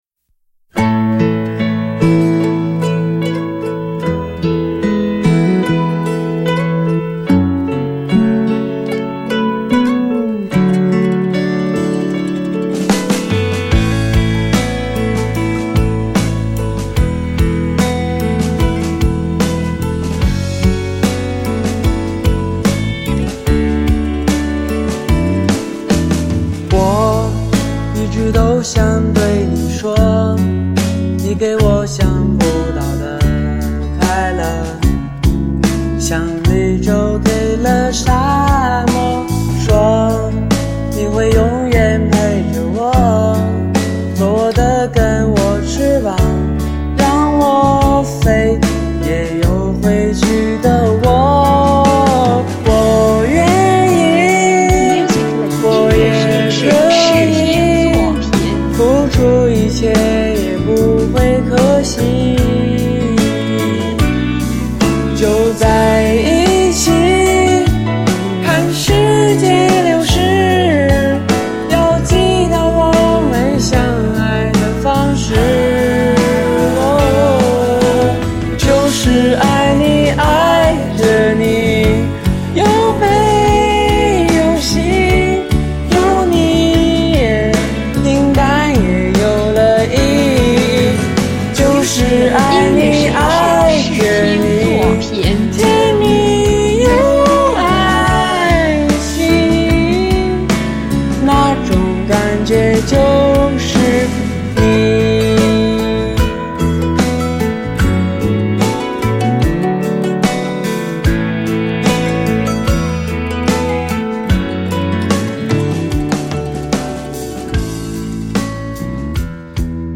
经过处理之后，相对解决了这些问题，但是也因为偏差明显，修改幅度过大，不可避免的出现了些许修音痕迹。